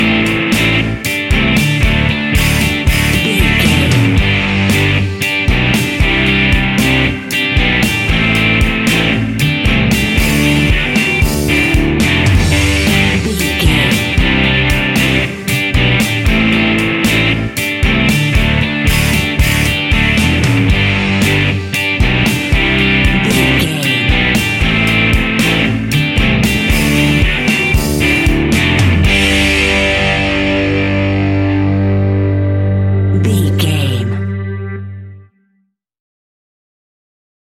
Uplifting
Ionian/Major
fun
energetic
acoustic guitars
drums
bass guitar
electric guitar
piano
organ